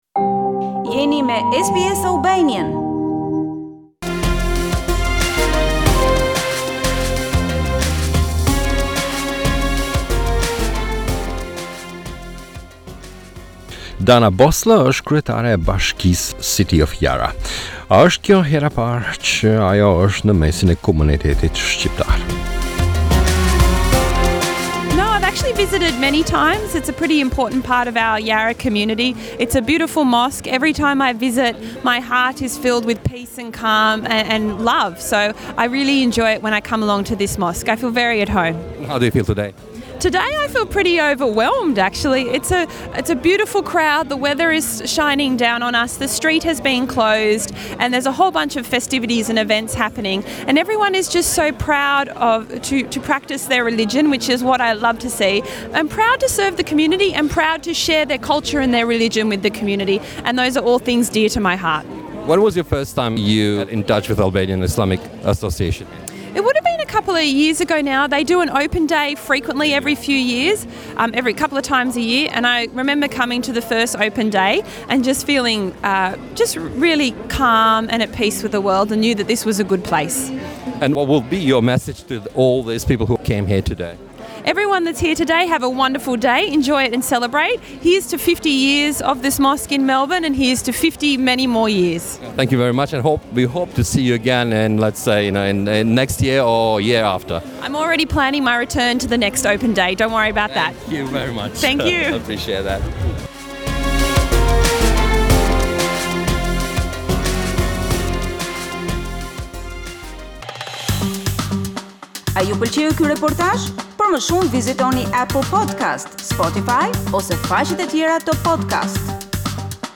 Danae Bosler - City of Yarra Mayor at 50th Anniversary of the Albanian Mosque